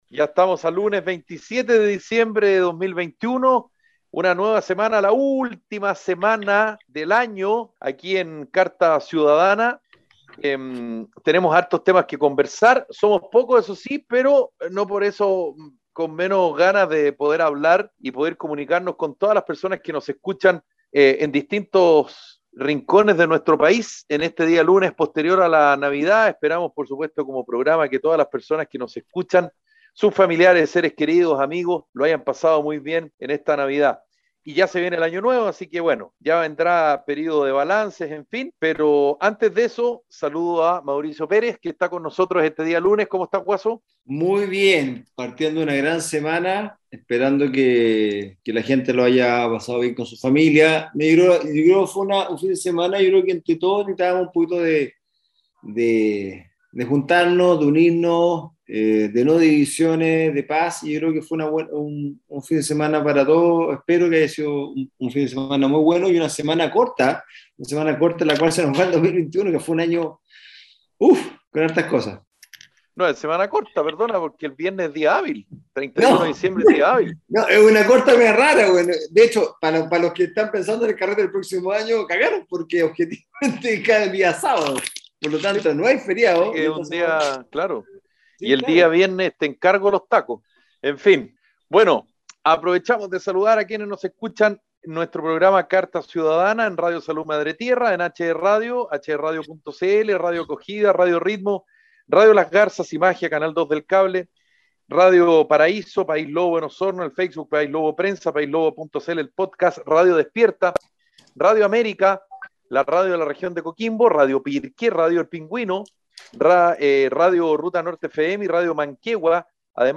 programa de conversación y análisis de la contingencia en Chile.